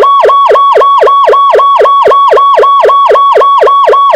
Whoop
gen_2_whoop.wav